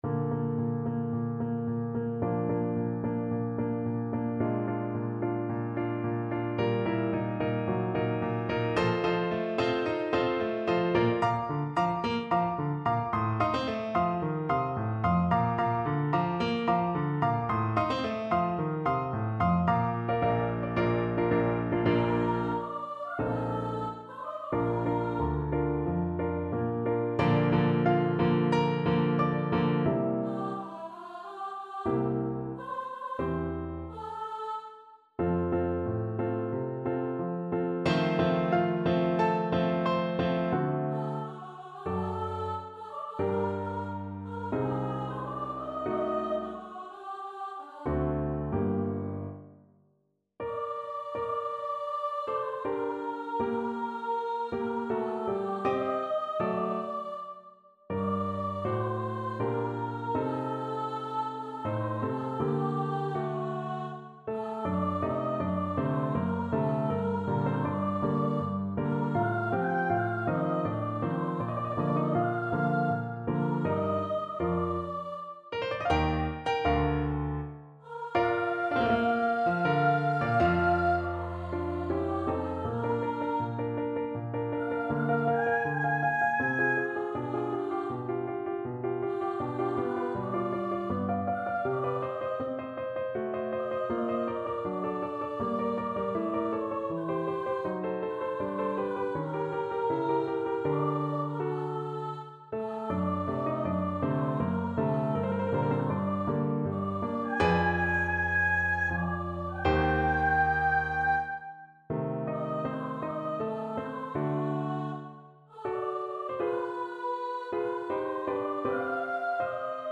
Allegro maestoso =110 (View more music marked Allegro)
D5-F7
Classical (View more Classical Soprano Voice Music)